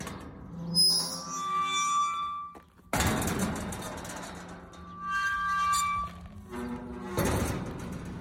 Bruit portail entrée (10/06/2024)